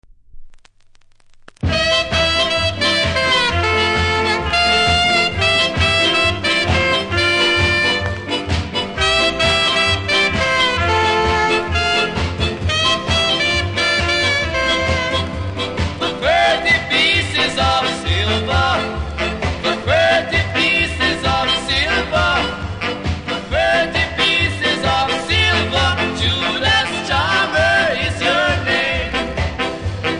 多少キズありますが音は良好なので試聴で確認下さい。